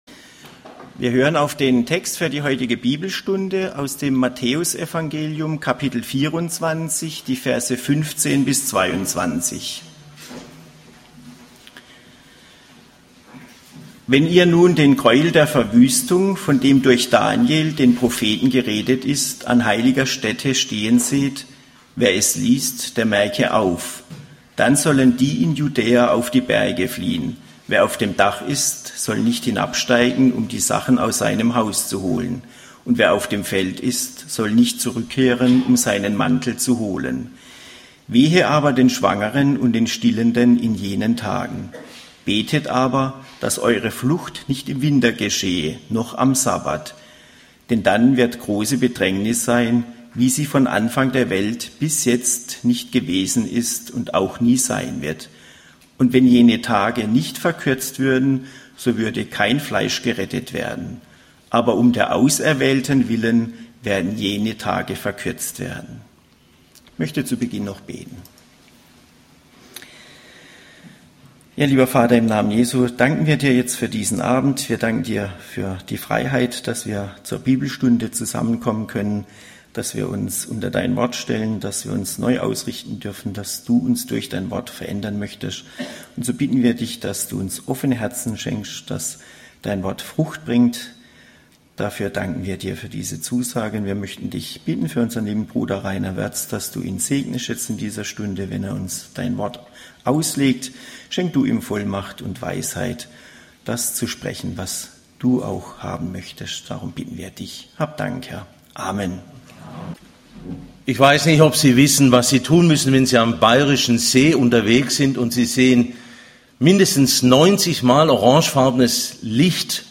Dienstart: Andere Vorträge